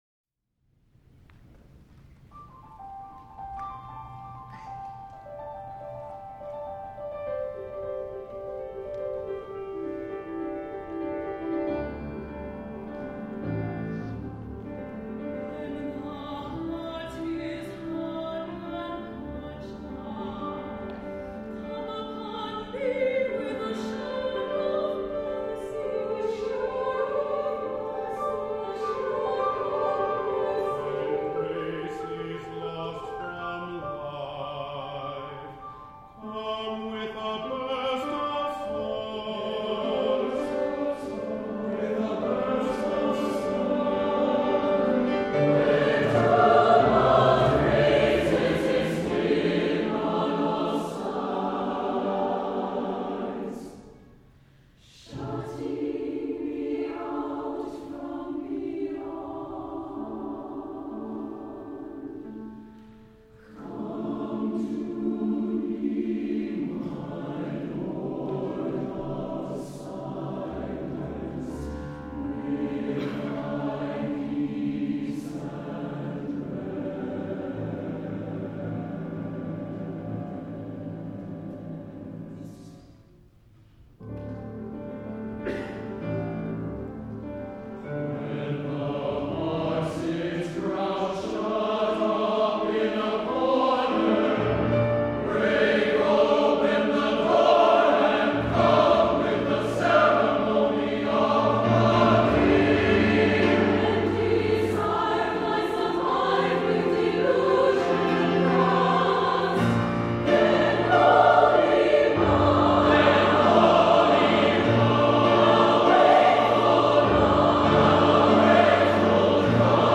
for SATB Chorus and Piano (2013)
The full chorus joins the soloist as the pathway opens.